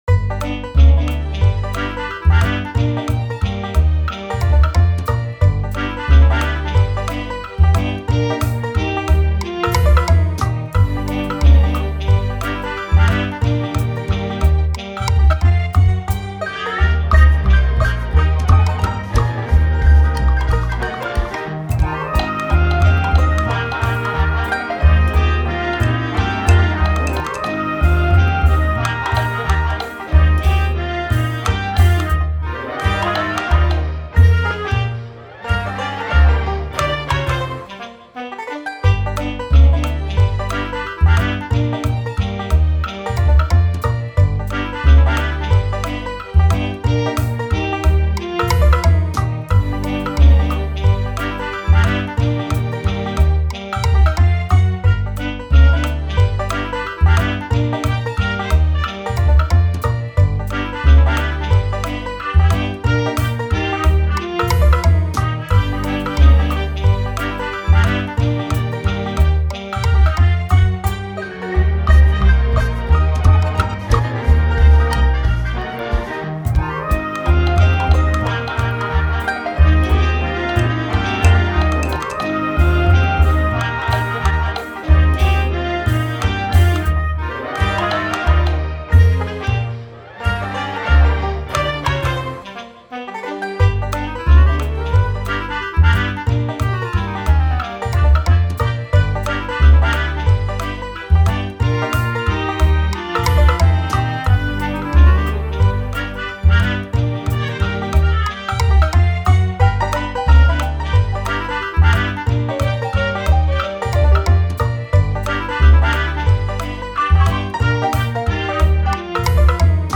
banda sonora
banjo
melodía